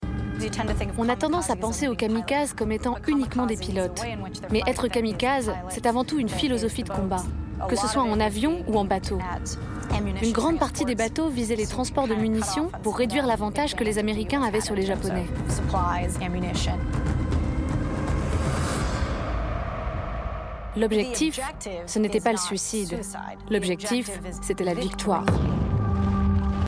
Voice Over - RMC Découverte